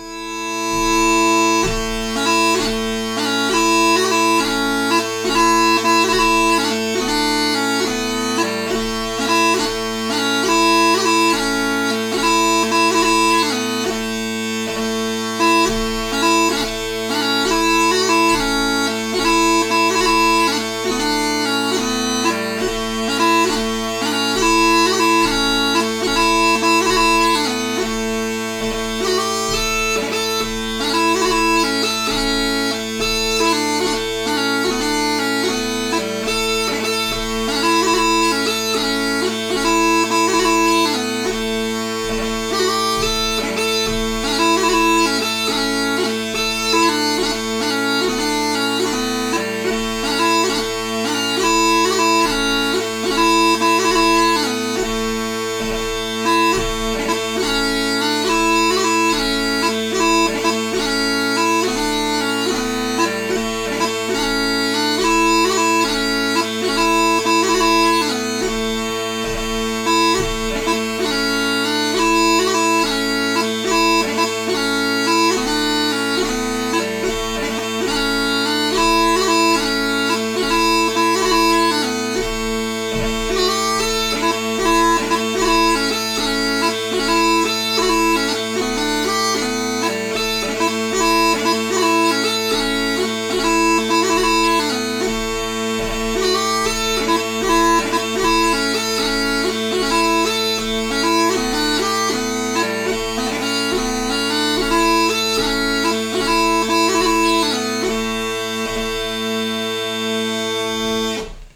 6/8 March by PM Donald Macleod
Recorded March 17, 2015 on Scottish smallpipe